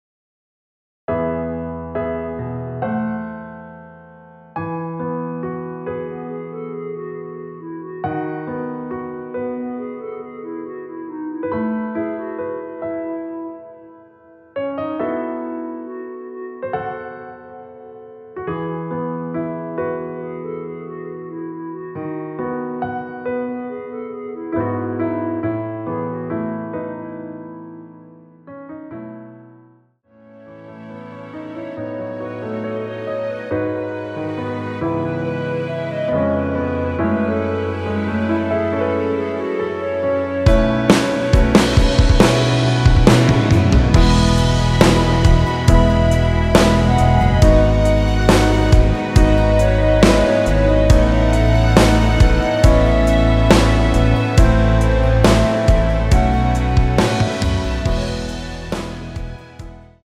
노래 들어가기 쉽게 전주 1마디 만들어 놓았습니다.(미리듣기 확인)
원키(1절앞+후렴)으로 진행되는 멜로디 포함된 MR입니다.
앞부분30초, 뒷부분30초씩 편집해서 올려 드리고 있습니다.